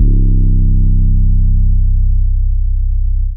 808 [gatti].wav